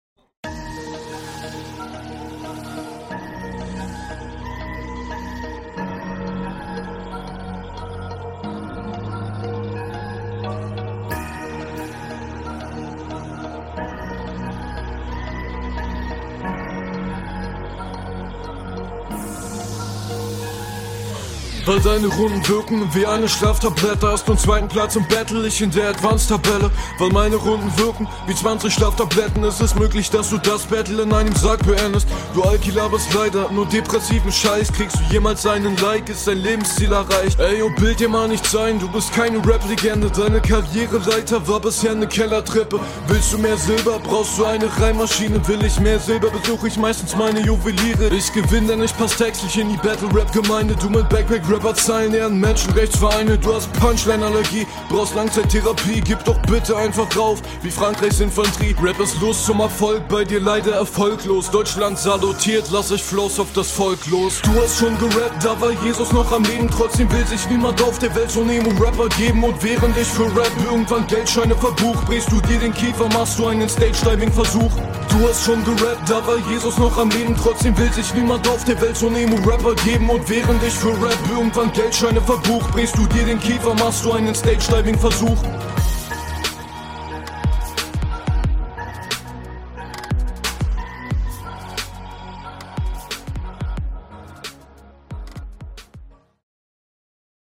Beat ist cool. An der Mische kann man nichts aussetzen.
Find den Beat nicht grade geil.